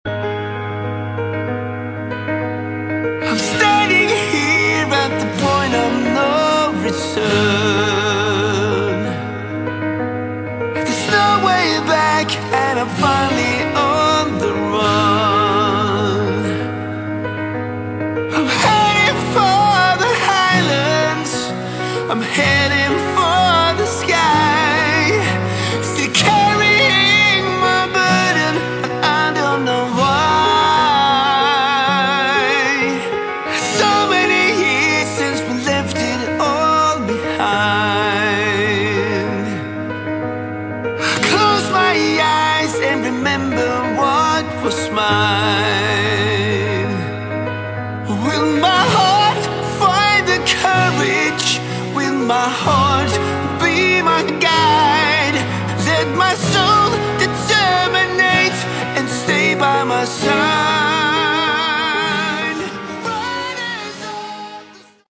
Category: Hard Rock
lead vocals
guitars, vocals
bass, vocals
drums, vocals